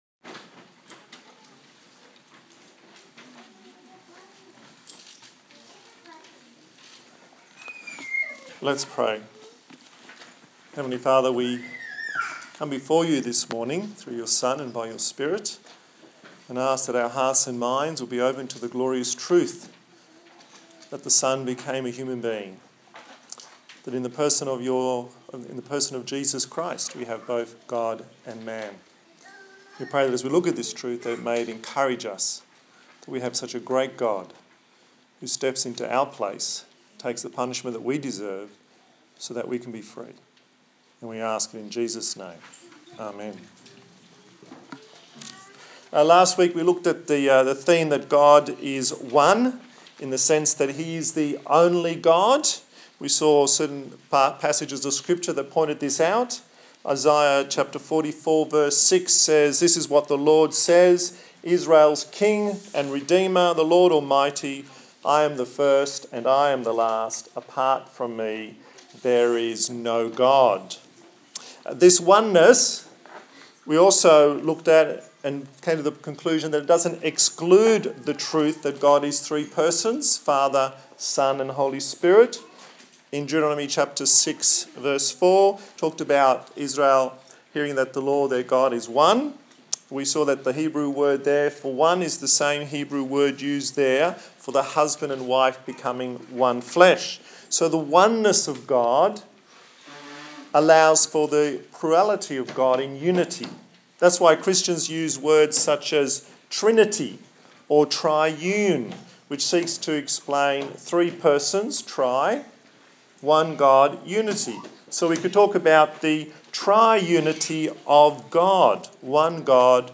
Passage: Hebrews 1:1-14 Service Type: Sunday Morning